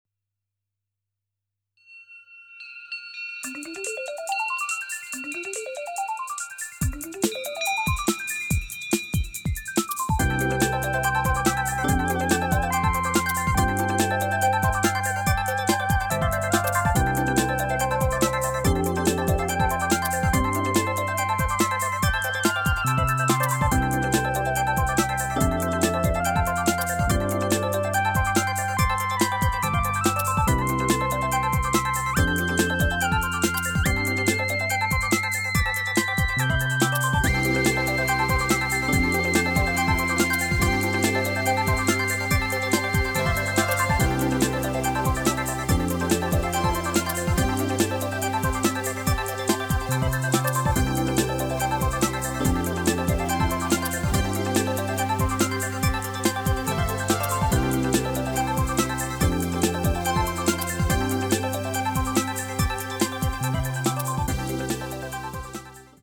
・ミッドは100Hz、-12db/octでHPF
・・・まぁ、少しパンチが減った音、って感じですかね。